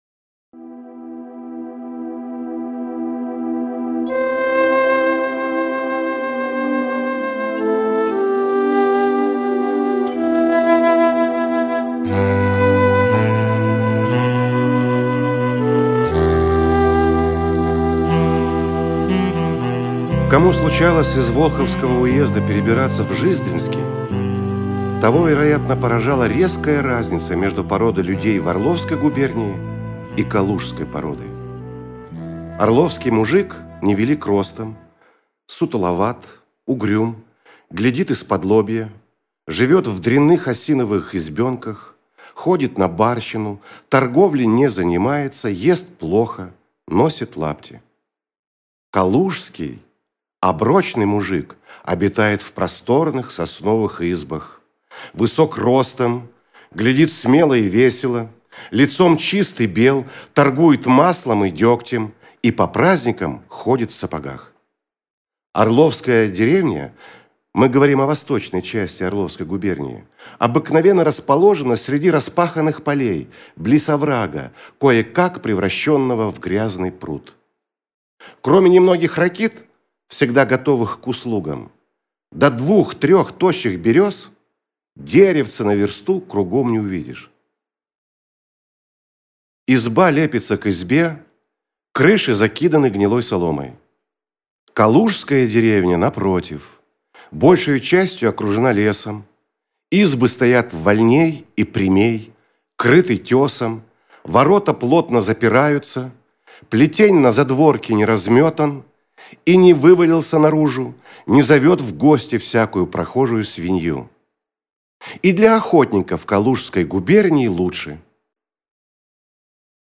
Хорь и Калиныч - аудио рассказ Тургенева И.С. Однажды рассказчик приехал на охоту к местному помещику Полутыкину, страстному любителю охоты...